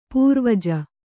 ♪ pūrvaja